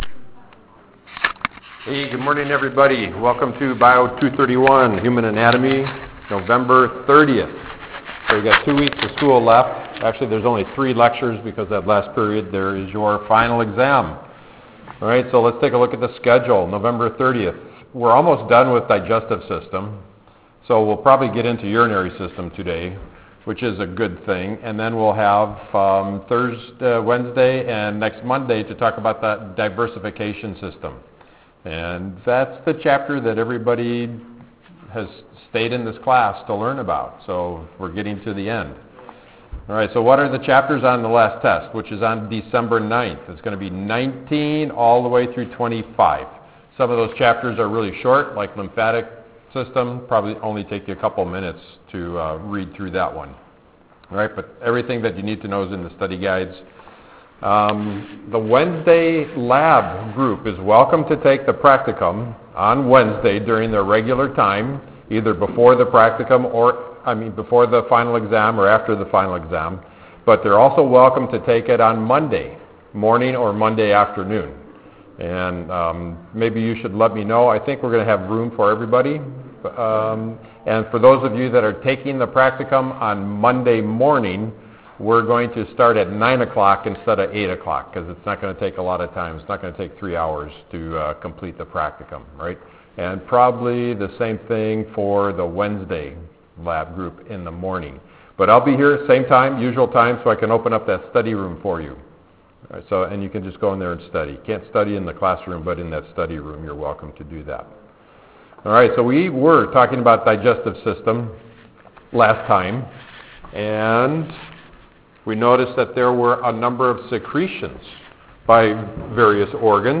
Fall 2015 Hybrid Biol 231, Human Anatomy Lectures